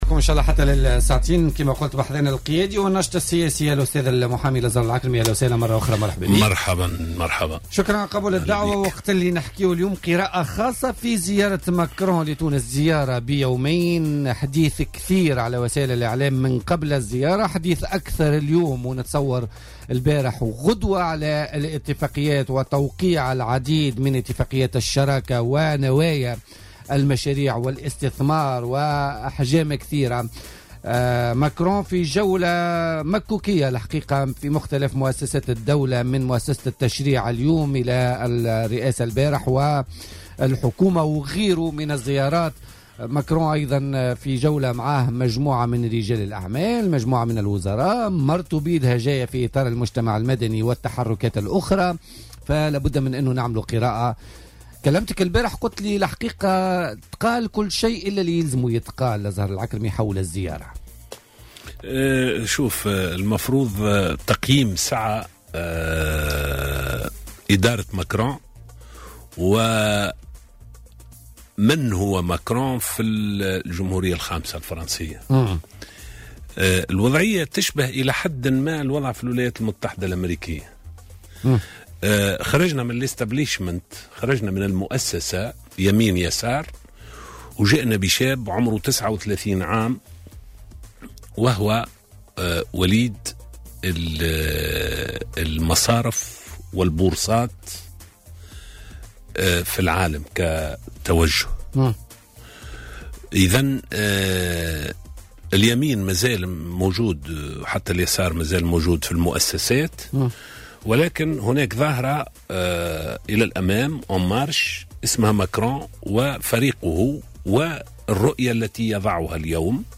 وأضاف ضيف "بوليتيكا" أن أولويات ماكرون هي اعادة الانتشار في الشرق الأوسط وأفريقيا وبخلفية أمنية بالأساس وليست اقتصادية.